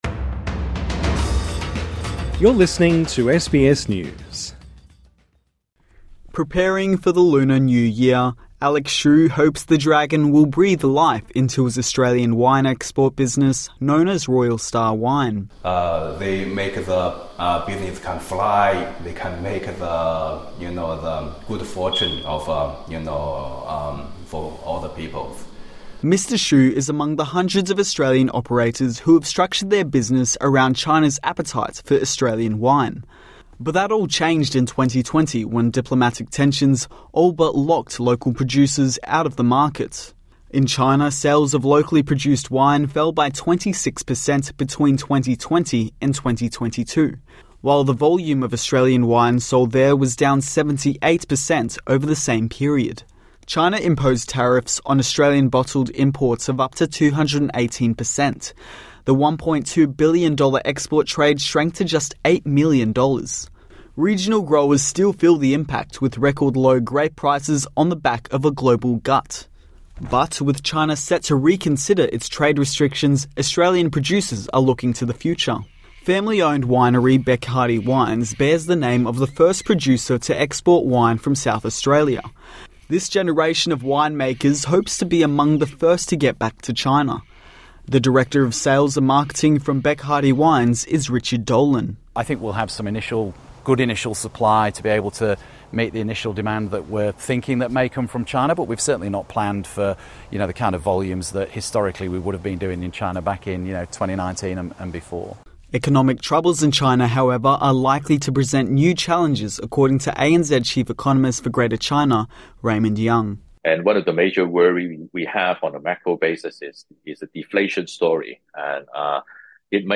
ฟังรายงานข่าวภาษาไทยโดยคลิก ▶ ด้านล่าง